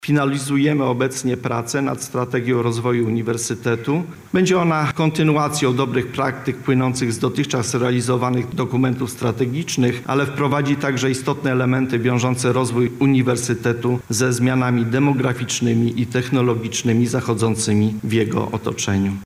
Wydarzenie miało miejsce na auli Wydziału Prawa i Administracji UMCS.